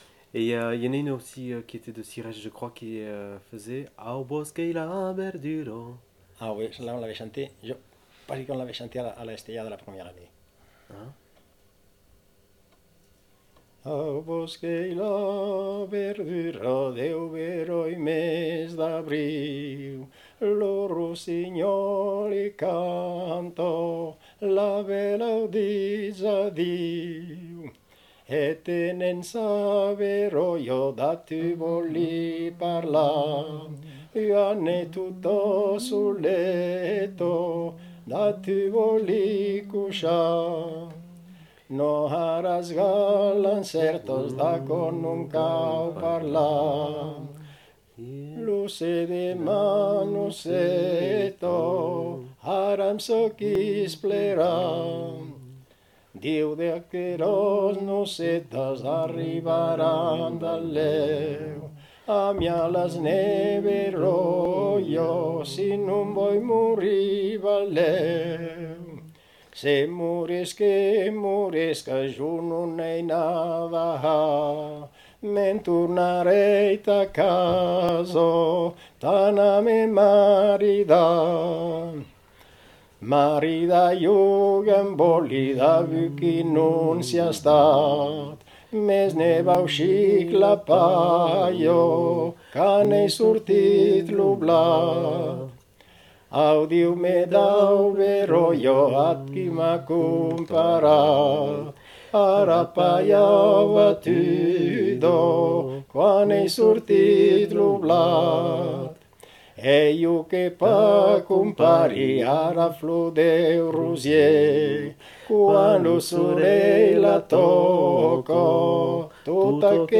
Aire culturelle : Bigorre
Lieu : Villelongue
Genre : chant
Effectif : 1
Type de voix : voix d'homme
Production du son : chanté